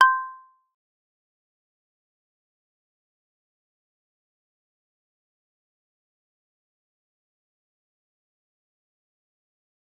G_Kalimba-C6-f.wav